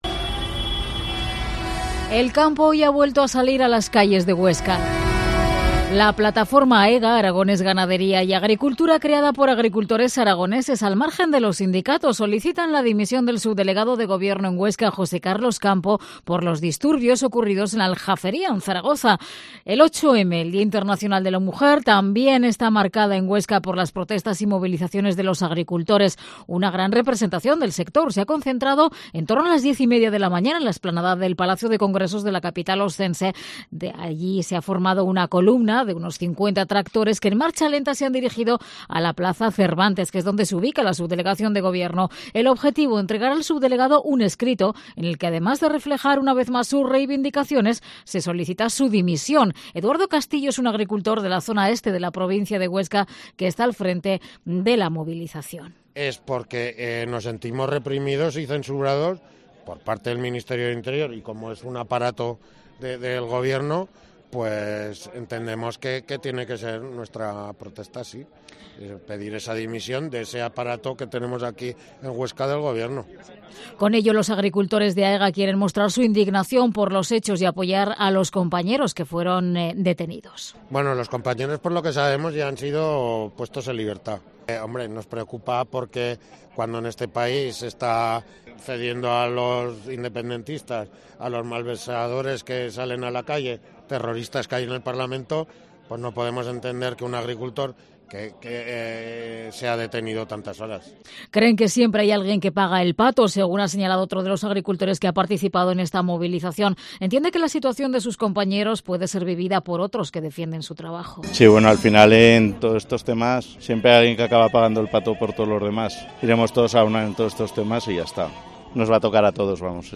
Reportaje de la tractorada 8M en Huesca